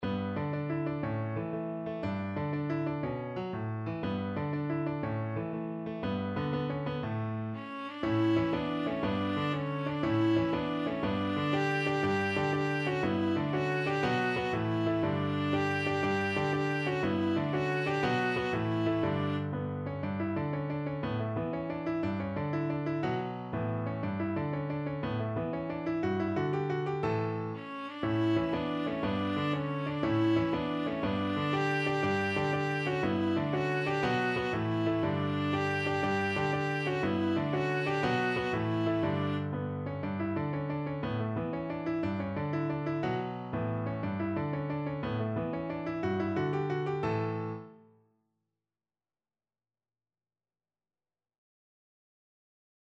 Classical Halle, Adam de la J'ai encore une tel paste from Le jeu de Robin et Marion Viola version
Viola
D major (Sounding Pitch) (View more D major Music for Viola )
With energy .=c.120
Classical (View more Classical Viola Music)